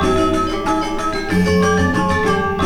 GAMELAN 7.wav